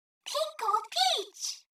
Pink Gold Peach voice clip